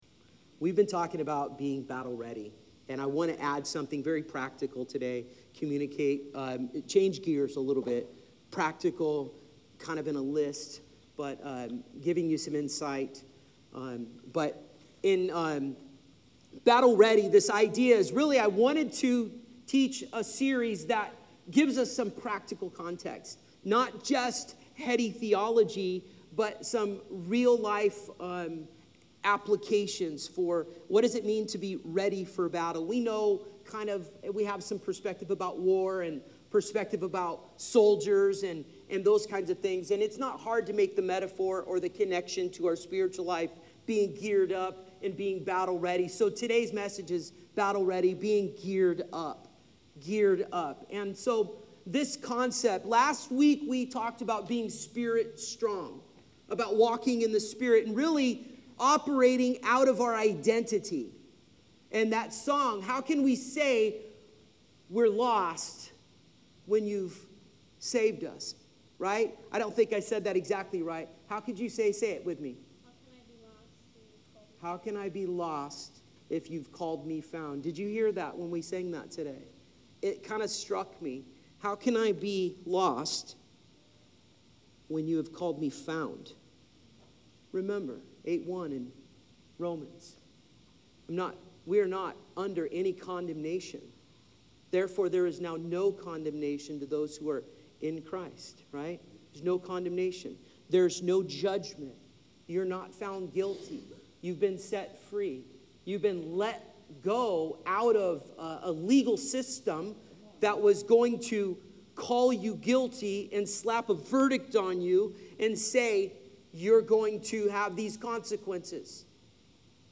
God never sends His people into battle unequipped.Come learn what the armor of God really means—and how to walk daily in truth, righteousness, peace, faith, and salvation. This message is both practical and powerful.